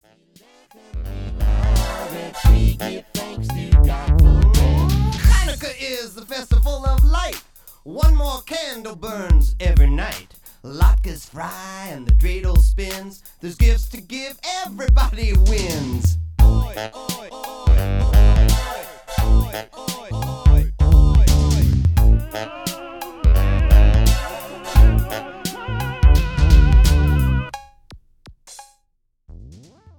Fun Jewish songs for all ages.
" and the Rap tune